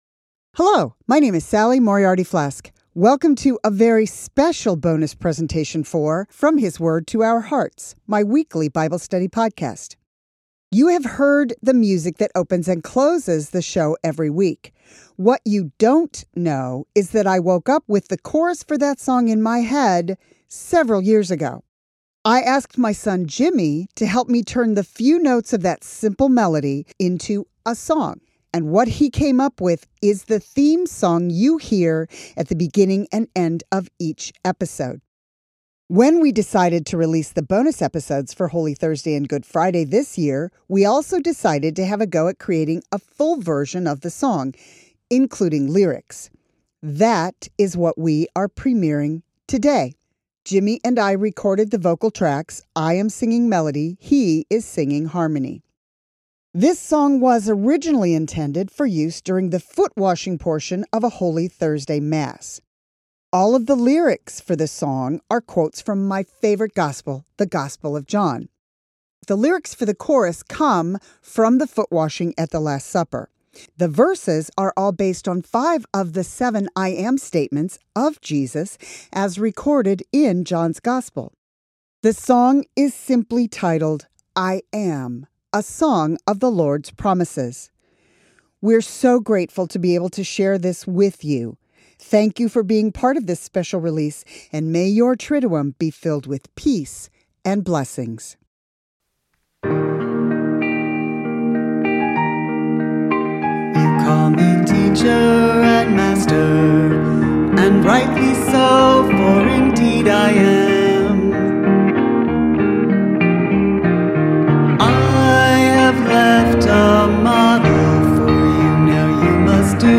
I am so grateful to be able to share with you a full version of the theme song for this podcast.